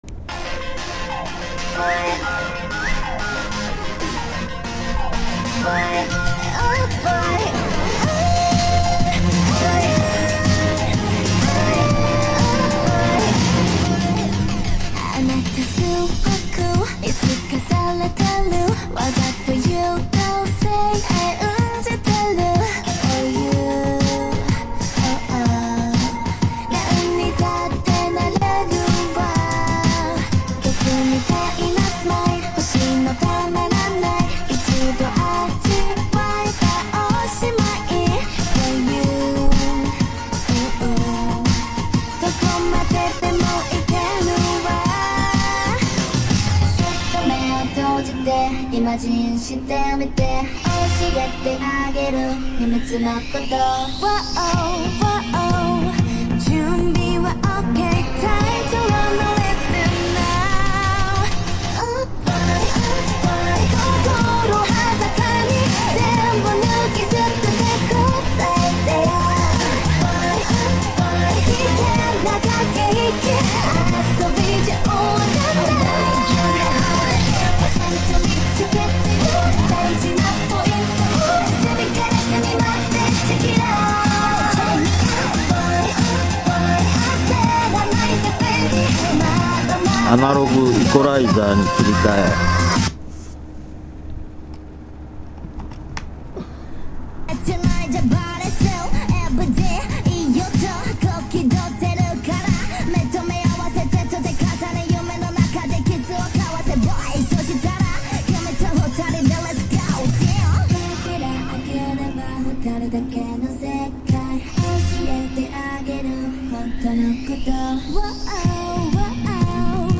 USBは問題ないですが、アナログ接続の方はピークでチリチリ言っていますね。やや歪っぽいです。